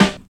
44 SNARE.wav